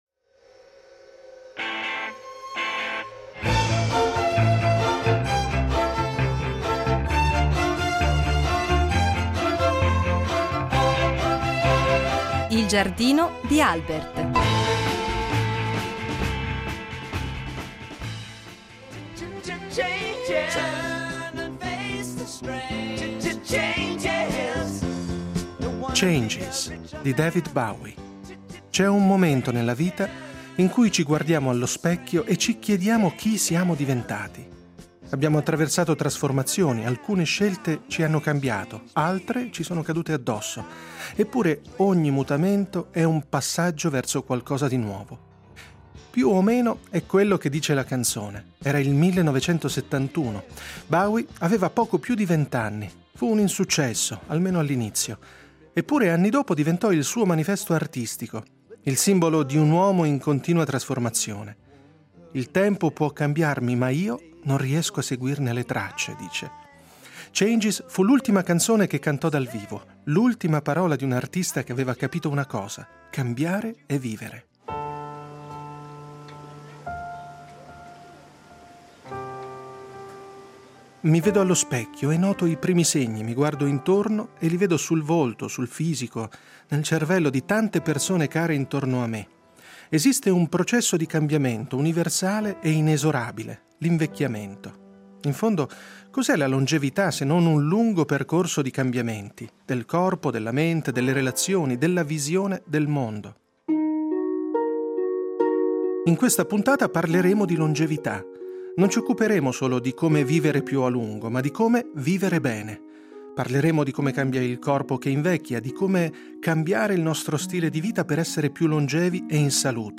In un dialogo con due scienziati di primo piano